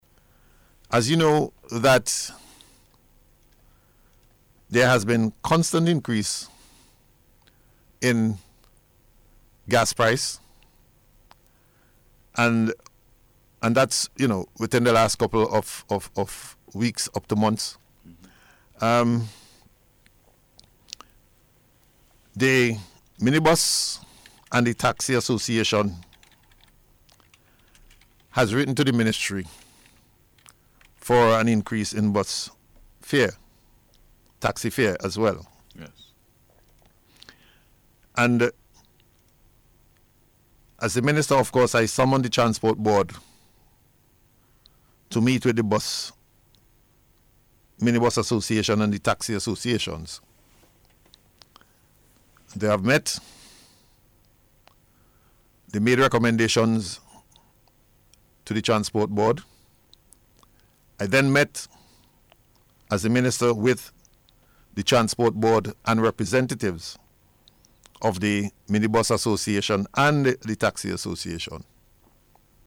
Minister Daniel made the announcement during NBC’s Face to Face program yesterday morning.
Acting Prime Minister and Minister of Transport and Works, lands and Physical Planning, Montgomery Daniel